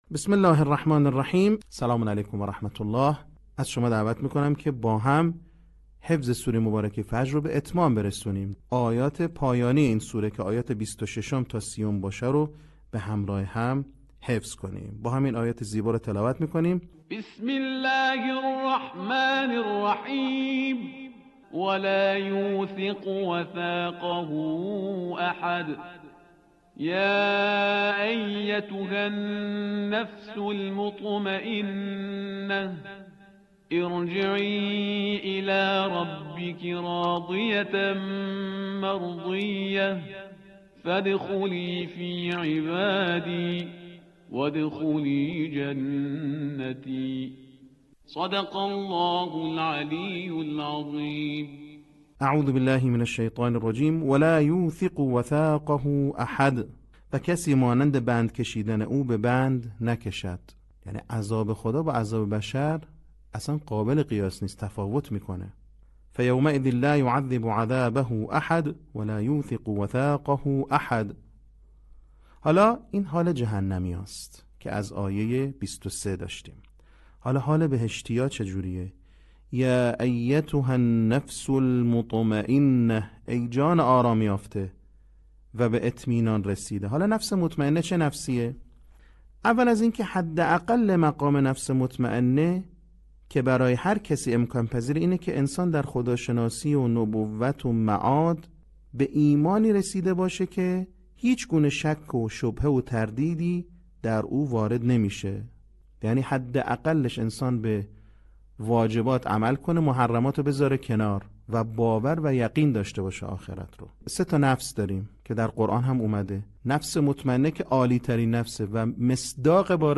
صوت | بخش هفتم آموزش حفظ سوره فجر